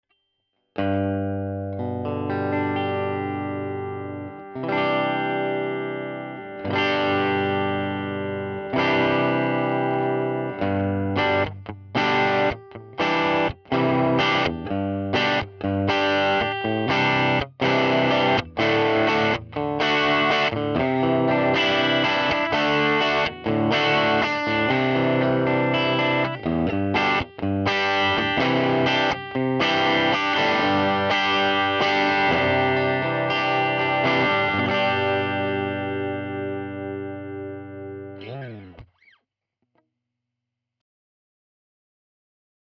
Hier mal schnell Tele am Nähkästchen, über 10" JensenTornado Unspektakulär, aber irgendwie ehrlich .. ich mags M88 Dein Browser kann diesen Sound nicht abspielen.